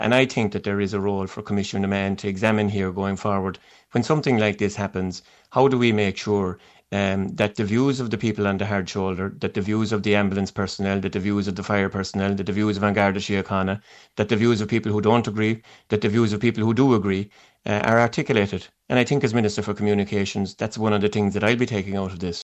He told Live 95 he feels the Government should have been given more opportunities to respond to concerns: